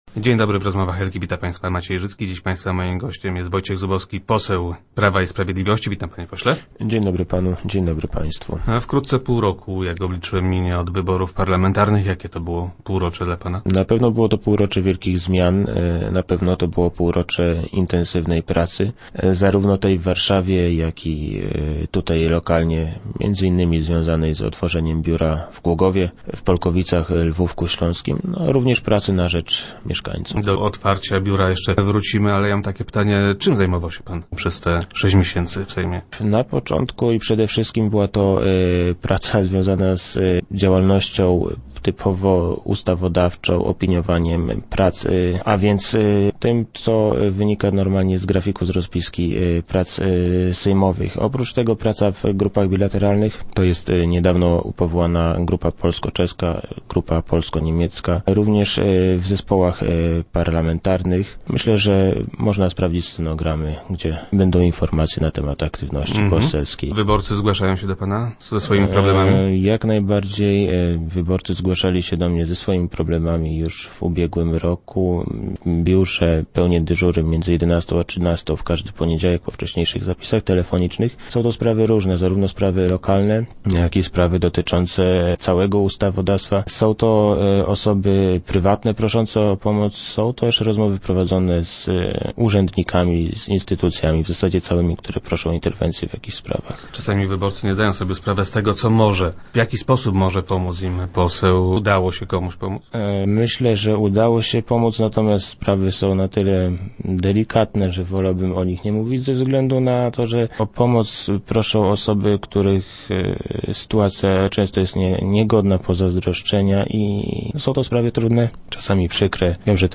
Jakie było dla niego ostatnie półrocze? - Pracy było całkiem sporo – twierdzi parlamentarzysta z Głogowa, który był gościem poniedziałkowych Rozmów Elki.
Jak powiedział w radiowym studio poseł PiS ci, którzy tak mówią mają wiele racji.